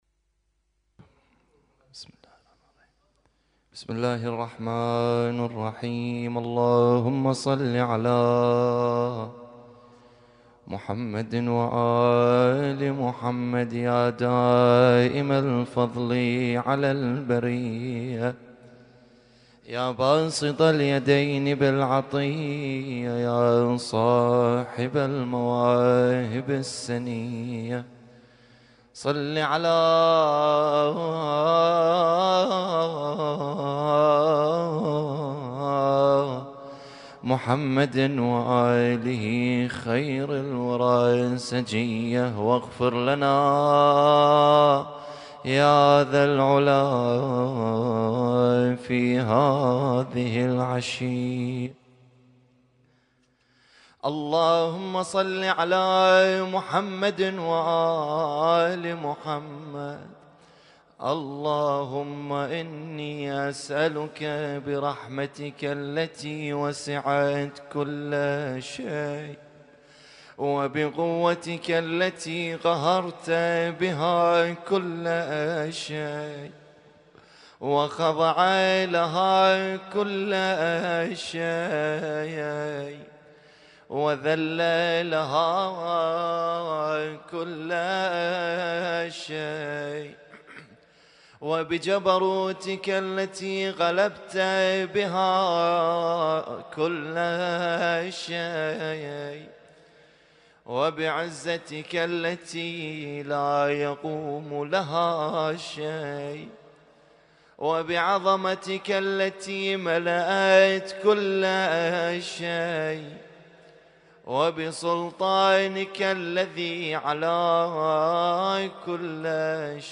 اسم التصنيف: المـكتبة الصــوتيه >> الادعية >> دعاء كميل